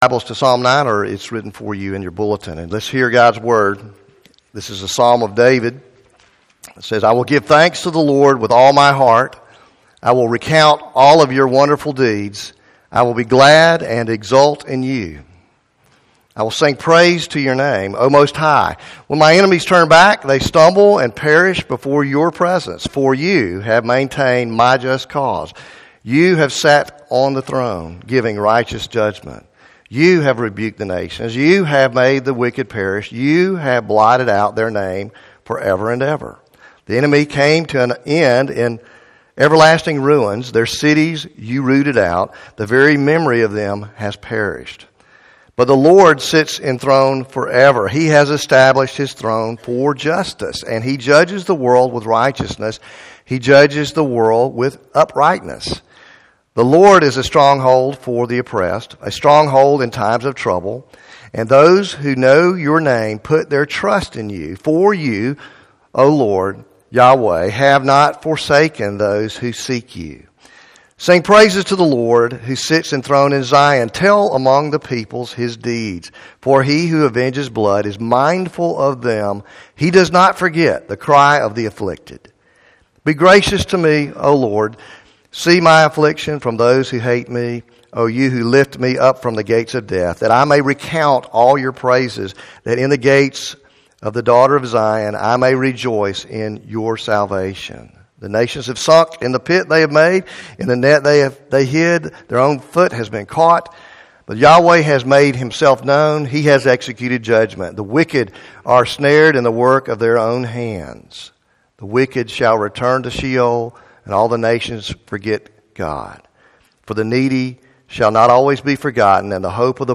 Faith Presbyterian Church PCA Sermons